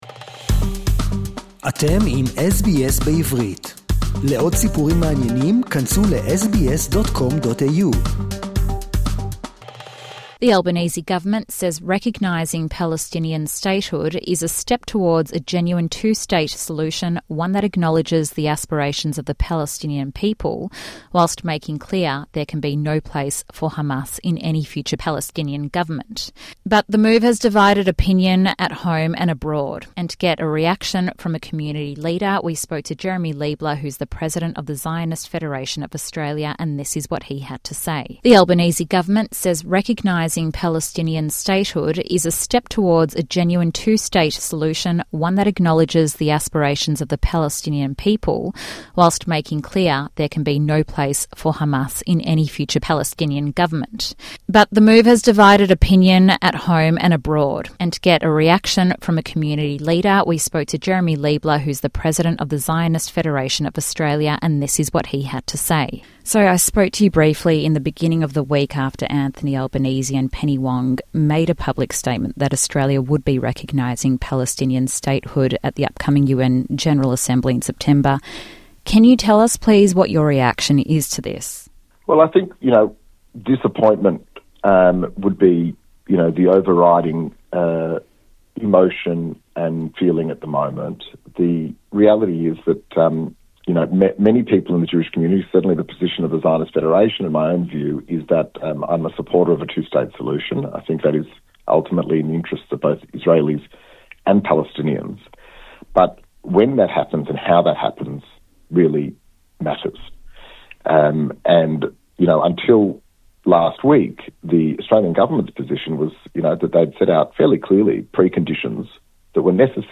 He joins us now to share his reaction.